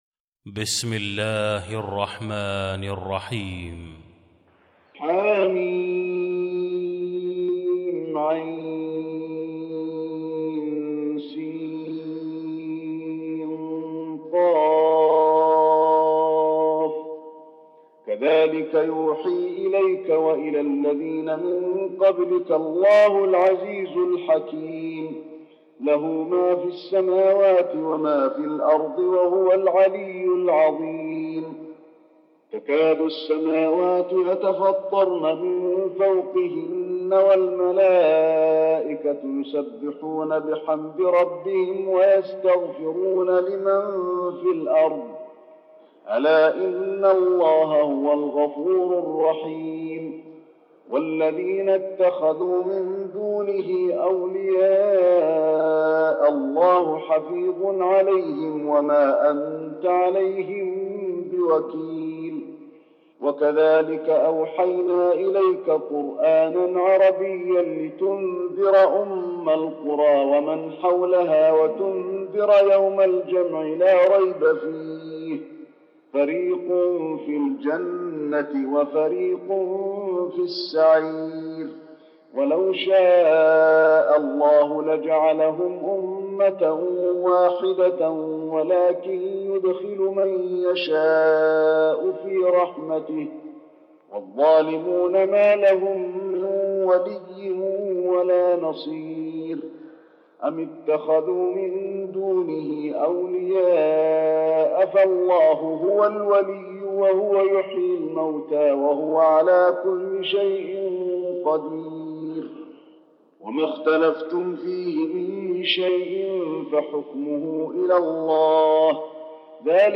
المكان: المسجد النبوي الشورى